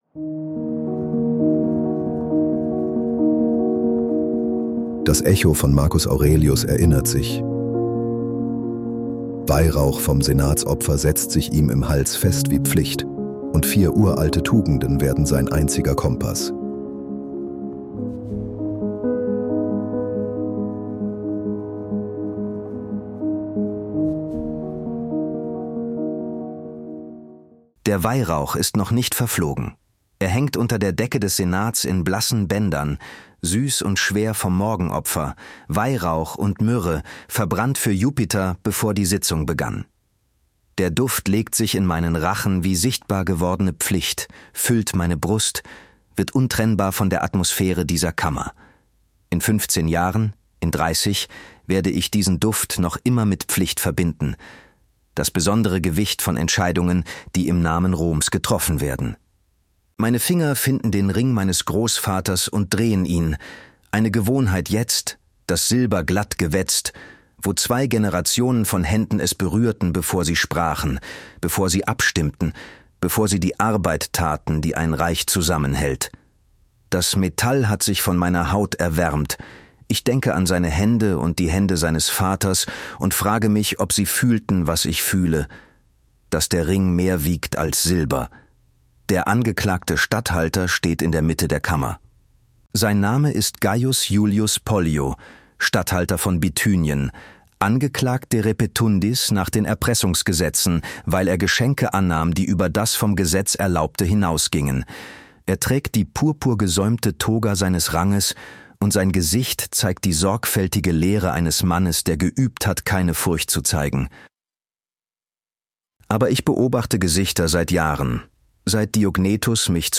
Wir nutzen synthetische Stimmen, damit diese Geschichten kostenlos bleiben, ohne Werbung — und dich in mehreren Sprachen erreichen.